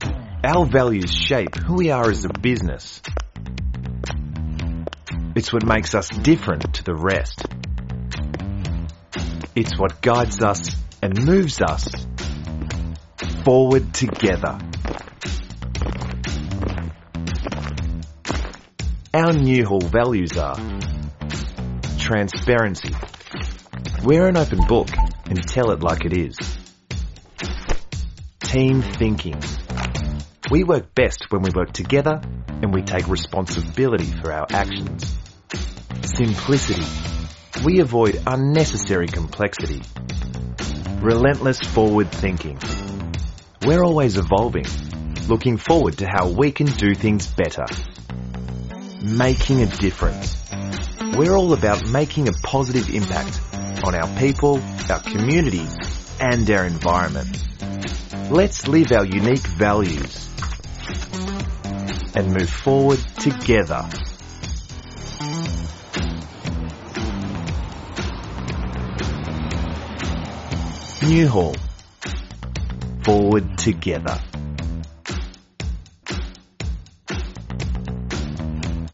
Vídeos Corporativos
Olá, sou uma dubladora profissional de inglês australiano com uma entrega calorosa, clara e versátil.
Inclui um microfone Rode NT1-A e uma interface de áudio, tudo instalado em uma cabine à prova de som para garantir excelente qualidade de gravação.